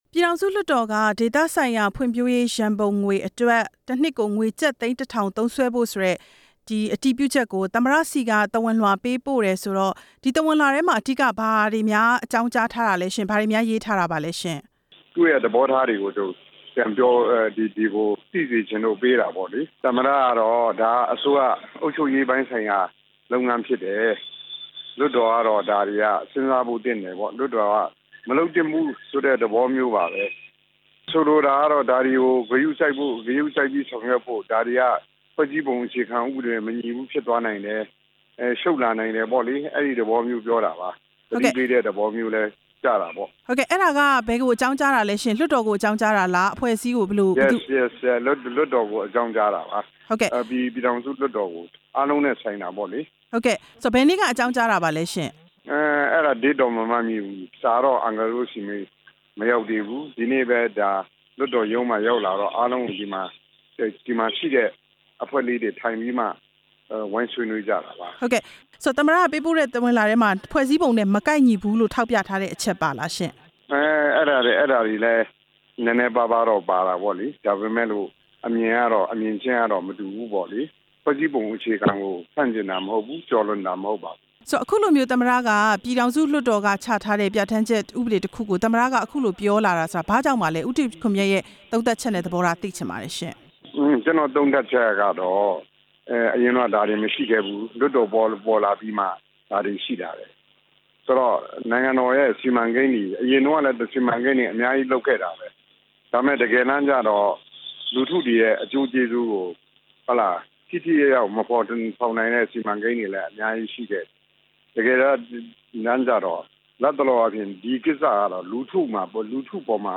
ပြည်သူ့လွှတ်တော် ဥပဒေကြမ်းကော်မတီဥက္ကဌ ဦးတီခွန်မြတ်နဲ့ မေးမြန်းချက်